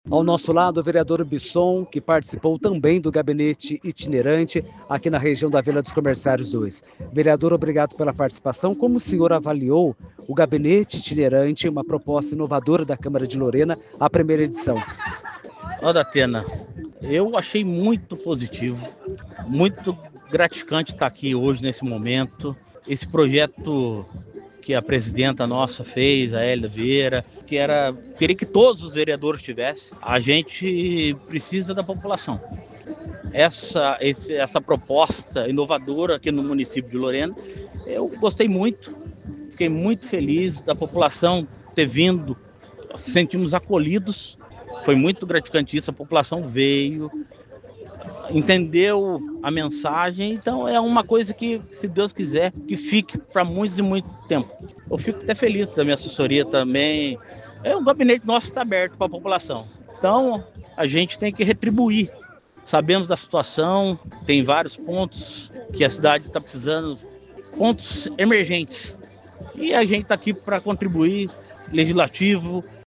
Áudio do vereador Robson Ribeiro Júlio (Bison – PL);